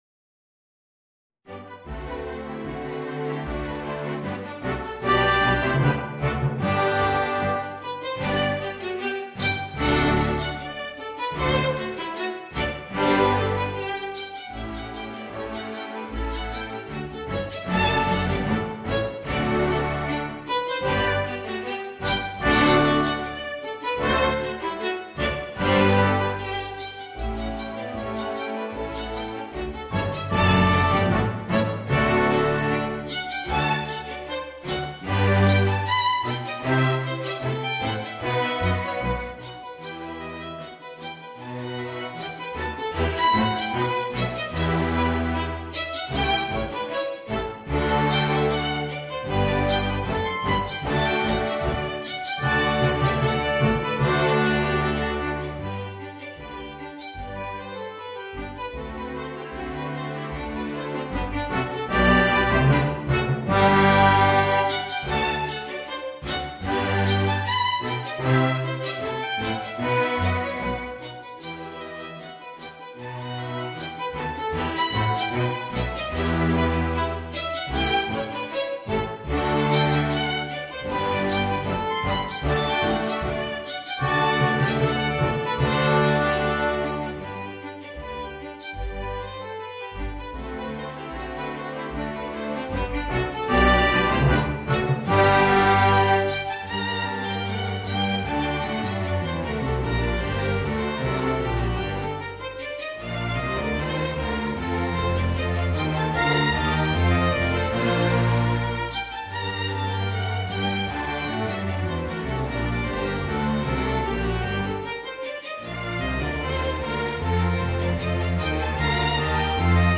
Voicing: Clarinet and Orchestra